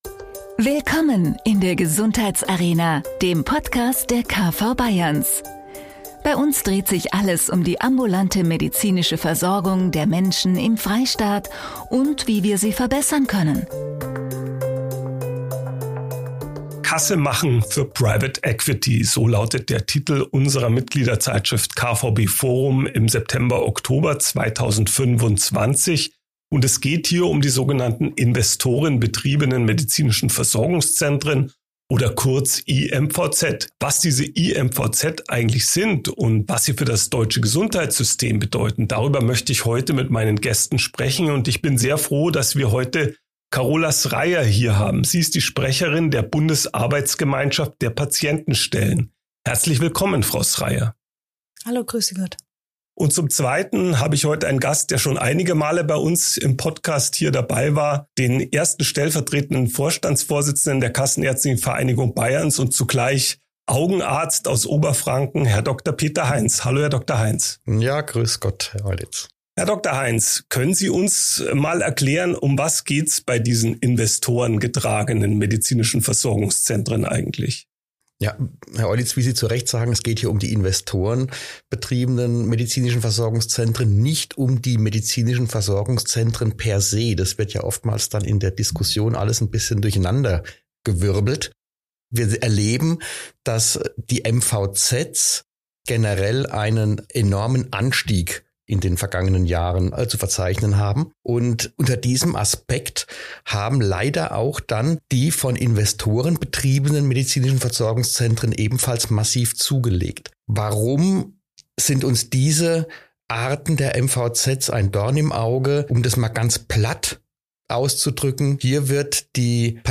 mit seinen beiden Gästen einem brisanten Thema der aktuellen Gesundheitsversorgung: investorenbetriebene Medizinische Versorgungszentren (iMVZ). Im Fokus steht nicht das MVZ-Modell an sich, sondern die zunehmende Kommerzialisierung der ambulanten Versorgung durch private Investoren – oft aus dem Ausland.